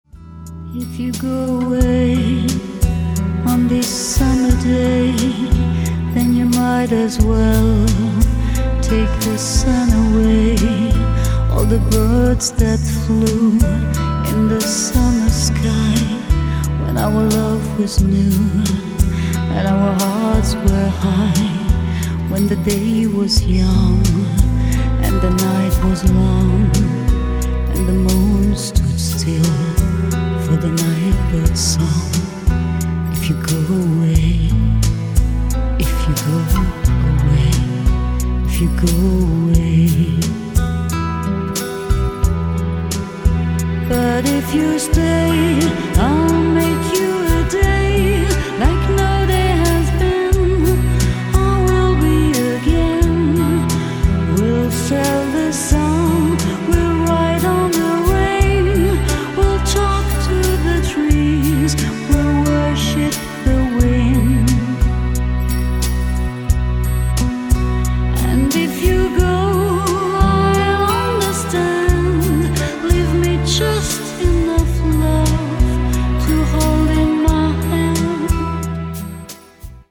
Романтические
красивые, спокойные, женский вокал